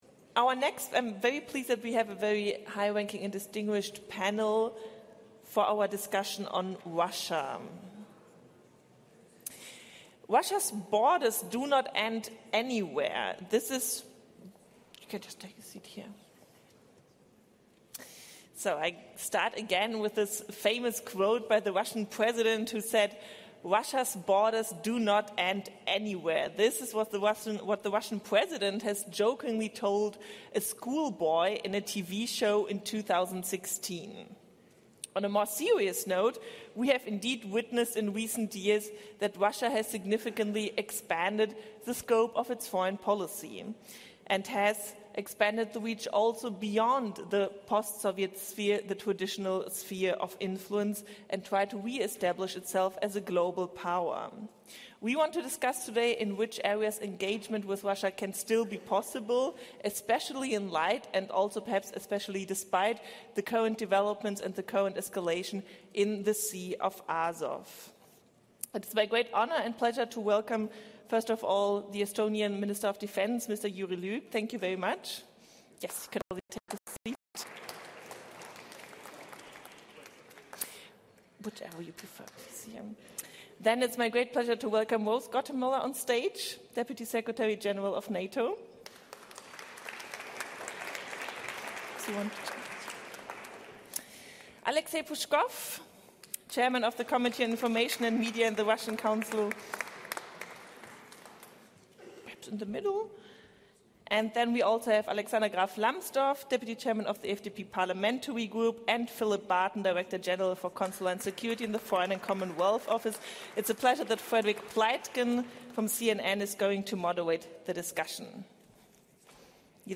The NATO Deputy Secretary General, Rose Gottemoeller, took part at Körber Stiftung’s Berlin Foreign Policy Forum on Tuesday (27 November). The Deputy Secretary General was on a panel discussion titled, ‘Russia Going Global? Prospects for Engagement’.